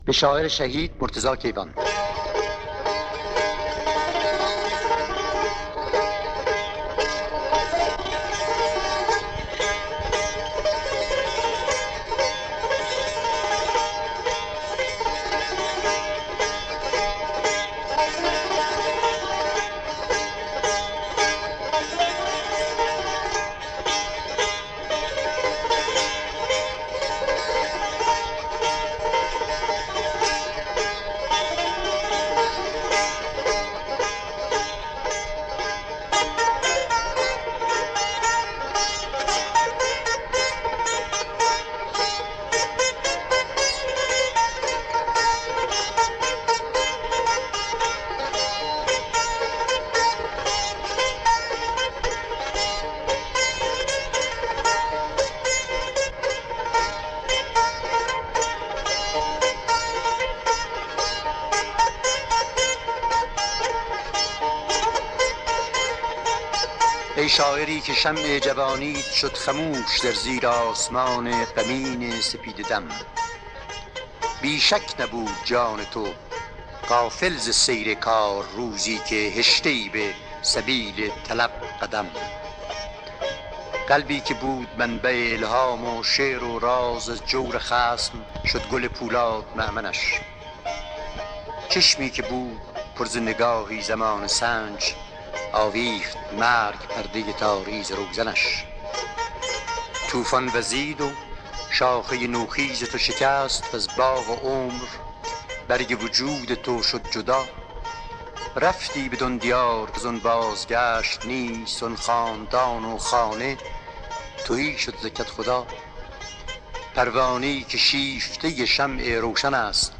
دانلود دکلمه به مرتضی کیوان با صدای احسان طبری با متن دکلمه
اطلاعات دکلمه